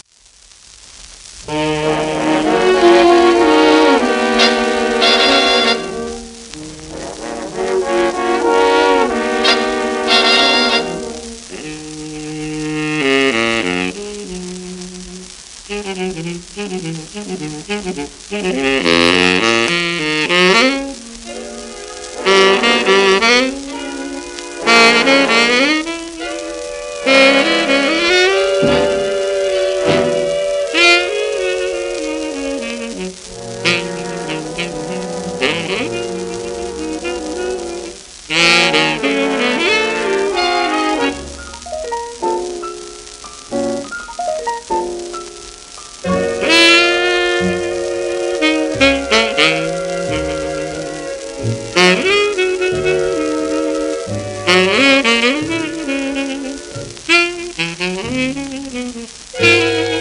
シェルマン アートワークスのSPレコード